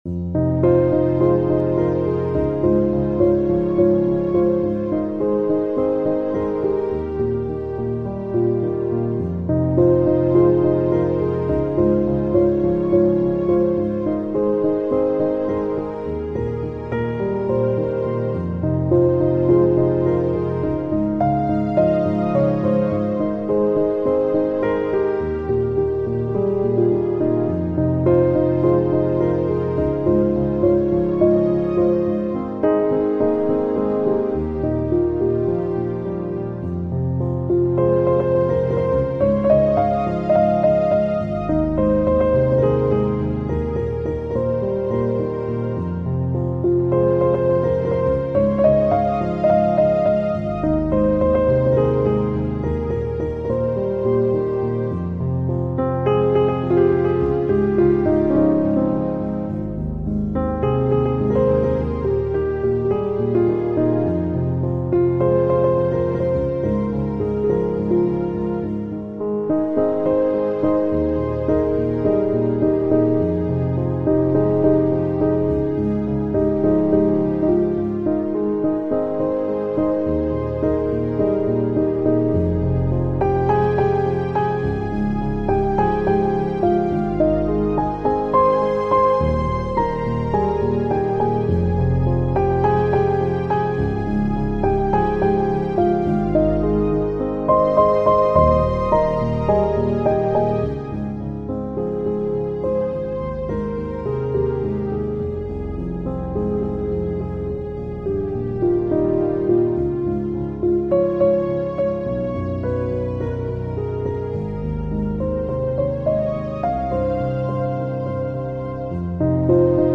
Electronic, Chill Out, Downtempo, Balearic, Lounge